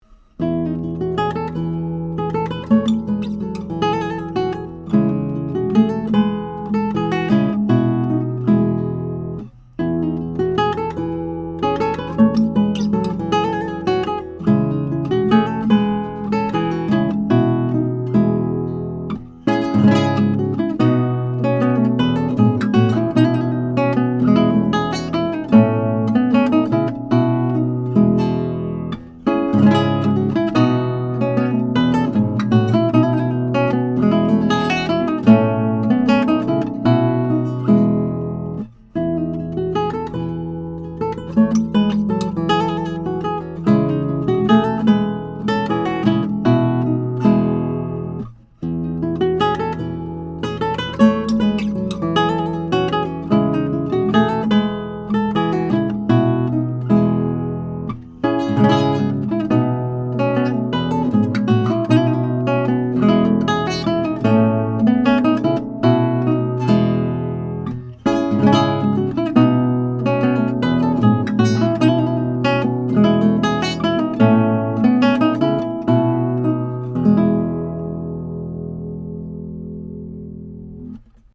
MILAGRO 11-String Classical Harp Guitar
I have the guitar tuned in Romantic tuning in to G, a standard 11-string tuning where 1-6 are tuned up a minor third, and 7-11 descend step-wise: 7=D, 8=C, 9=B, 10 =A, 11=G. It can also be tuned in Dm tuning for Baroque Dm lute music.
Here are 12 quick, 1-take MP3s of this guitar, tracked using a Neumann TLM67 mic, into a Undertone Audio MPEQ-1 preamp using a Metric Halo ULN8 converter going into Logic. This is straight, pure signal with no additional reverb, EQ or any other effects.
(Italian Renaissance)